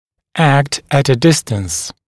[ækt ət ə ‘dɪstəns][экт эт э ‘дистэнс]действовать на удалении